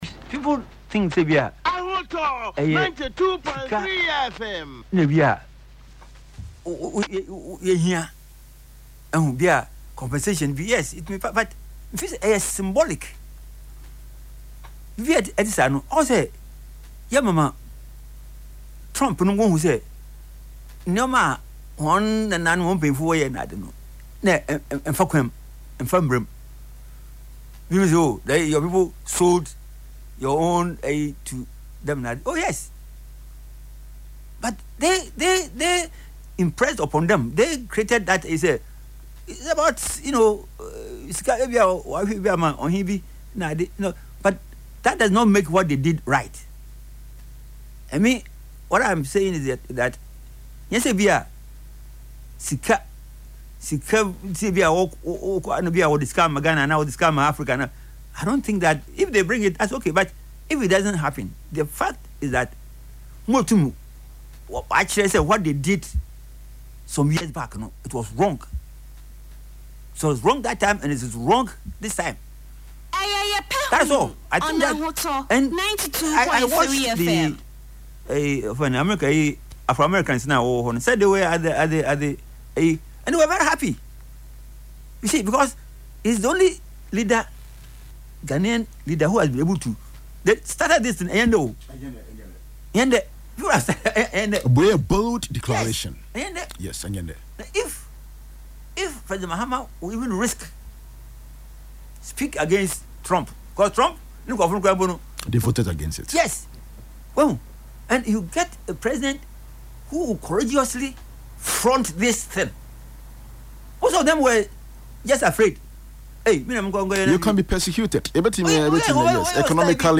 Speaking on Ahotor FM’s Yepe Ahunu show on Saturday, March 28